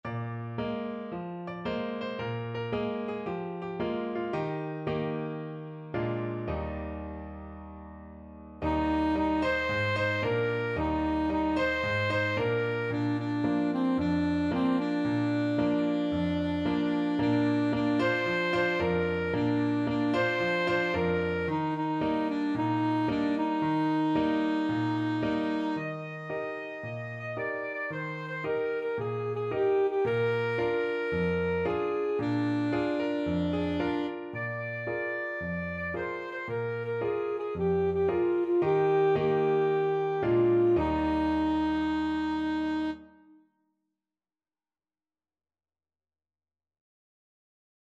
Alto Saxophone
Eb major (Sounding Pitch) C major (Alto Saxophone in Eb) (View more Eb major Music for Saxophone )
4/4 (View more 4/4 Music)
Cheerfully! =c.112
Traditional (View more Traditional Saxophone Music)